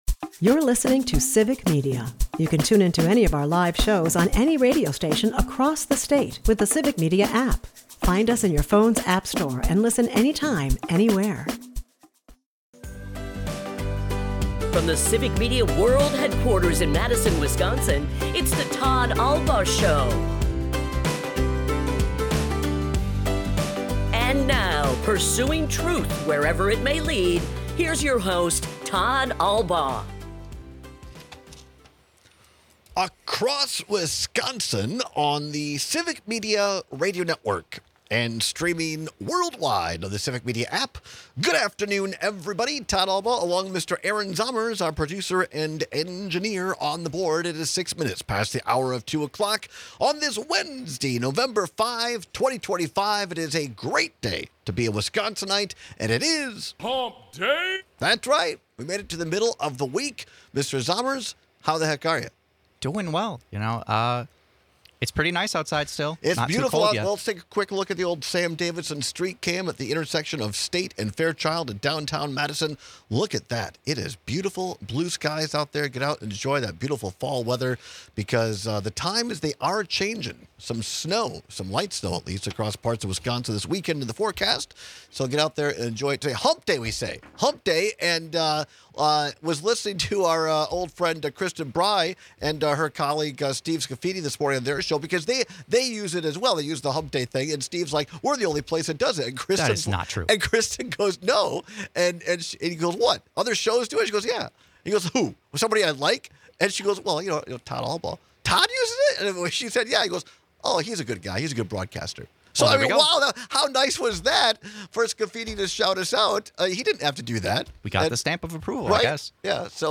At the bottom of the hour, State Senator and gubernatorial candidate Kelda Roys stops by to share her analysis of yesterday’s election results. We may not have had an election here in Wisconsin, but Sen. Roys says she believes there are lessons to be applied in our state.